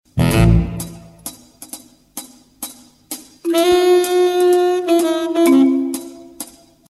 На этой странице собраны звуки, передающие атмосферу размышлений: задумчивые паузы, едва уловимые вздохи, фоновое бормотание.